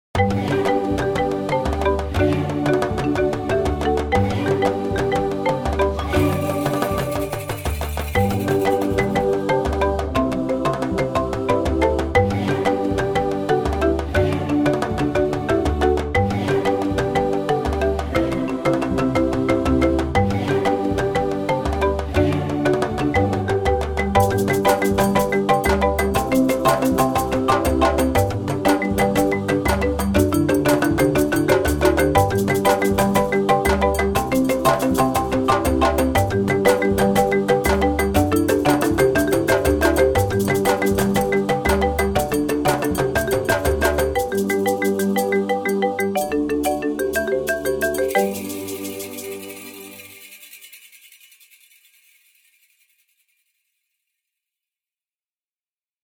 Special (instrumental)
special-g-inst.mp3